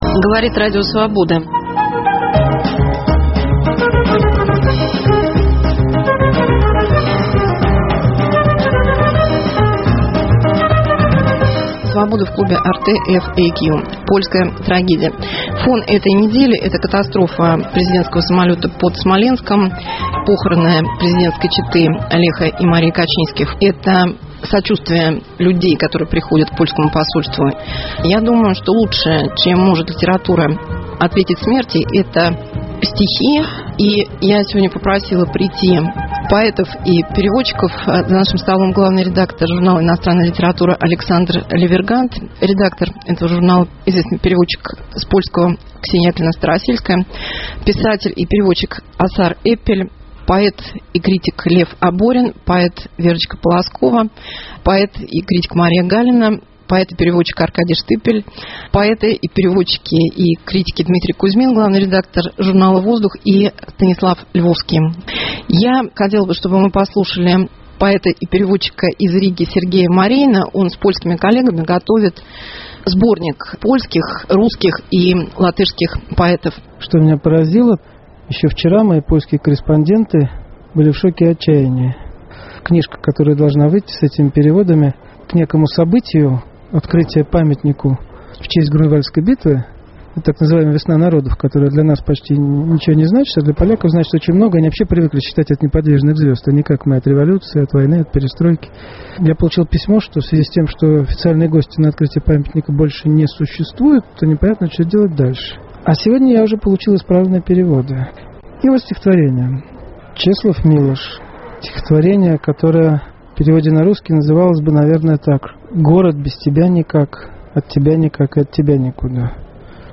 Русские поэты и переводчики читают польские стихи и говорят о польском национальном характере.